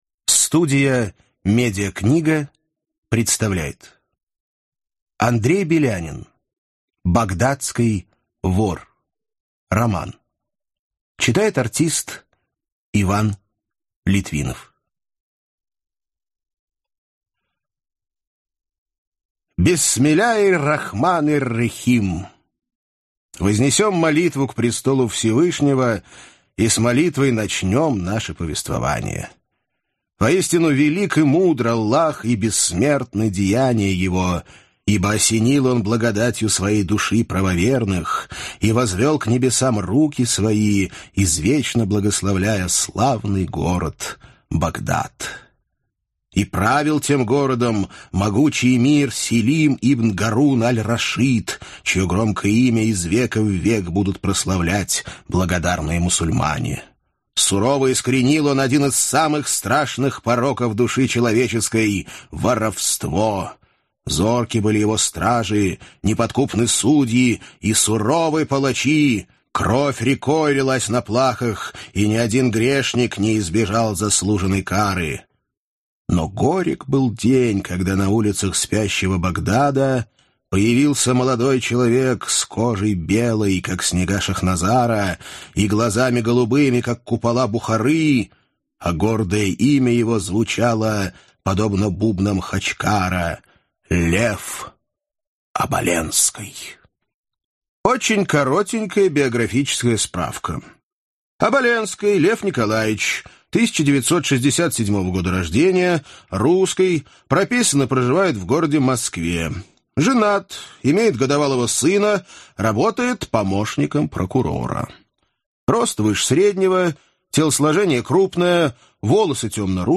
Аудиокнига Багдадский вор | Библиотека аудиокниг
Прослушать и бесплатно скачать фрагмент аудиокниги